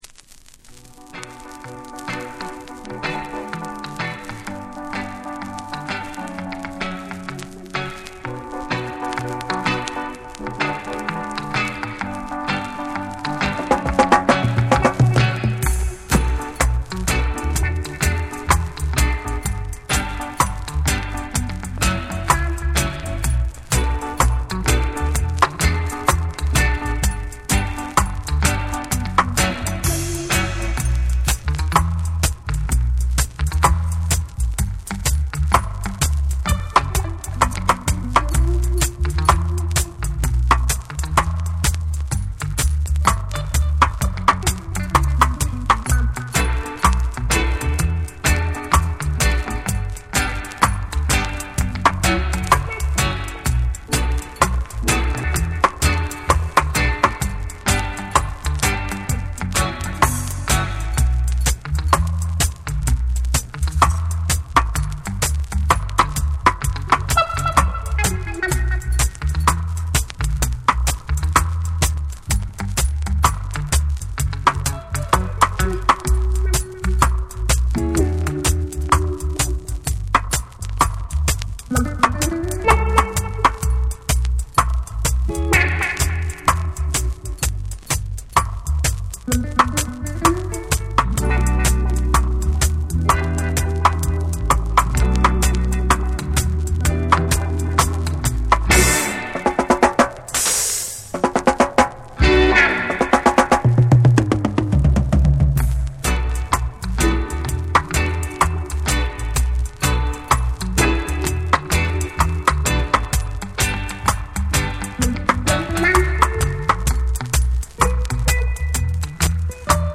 スモーキーなダブ処理と空間的なミックスで別次元の魅力を放つ好内容！
※チリノイズあり。